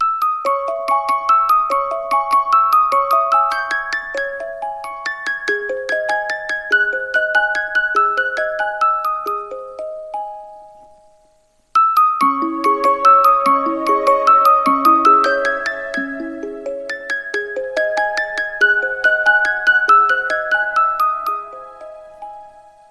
• Качество: 112, Stereo
Стандартный рингтон